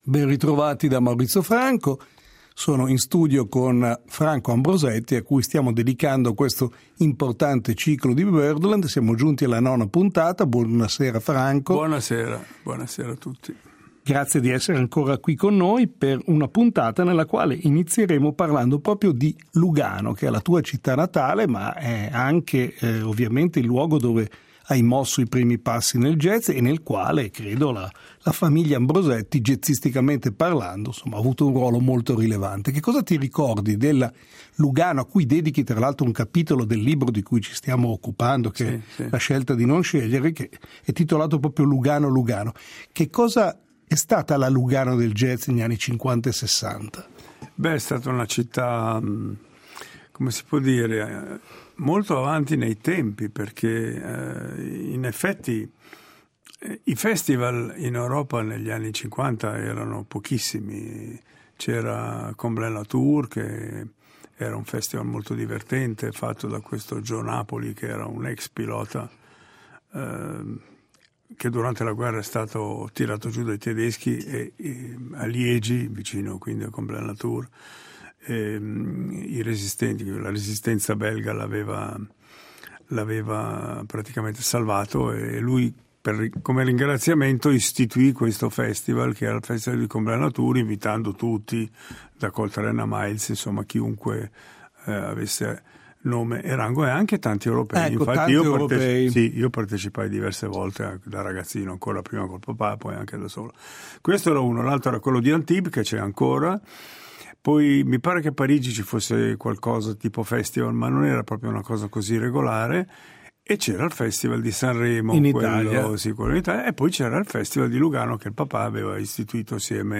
Trombettista e flicornista, iniziò la carriera giovanissimo nei primi '60 accanto al padre Flavio, altosassofonista e pioniere del jazz moderno in Svizzera ed Europa.